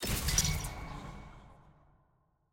sfx-jfe-ui-warning-enter.ogg